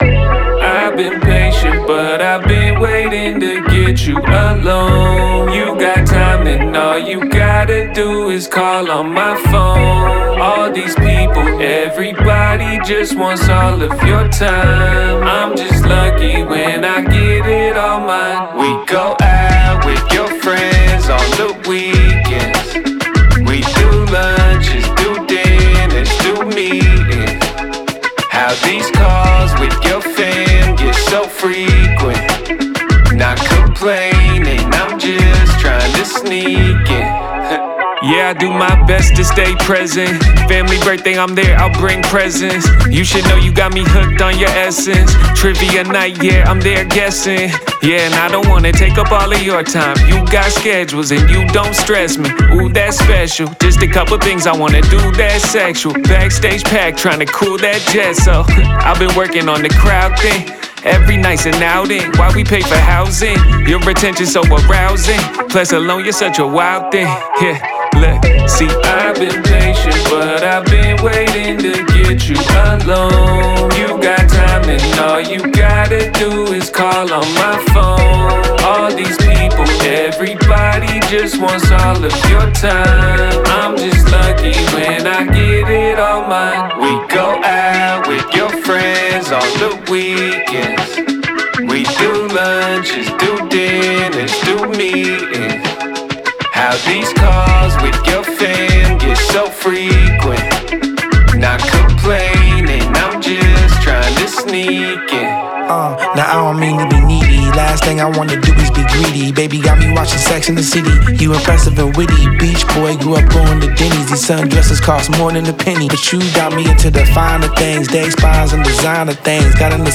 upbeat pop rap / alt rap single